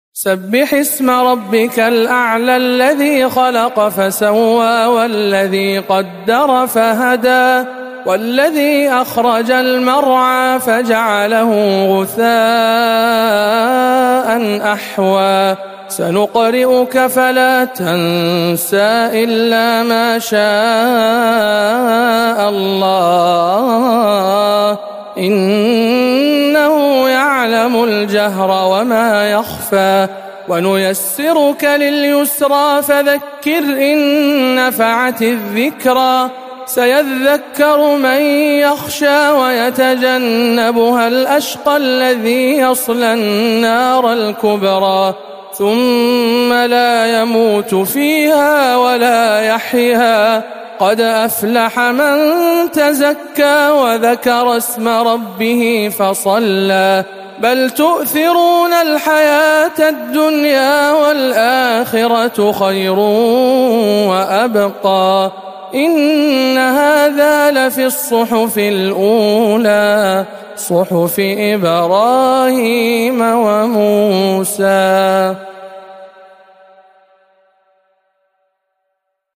سورة الأعلى بجامع أم الخير بجدة - رمضان 1439 هـ